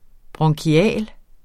Udtale [ bʁʌŋkiˈæˀl ]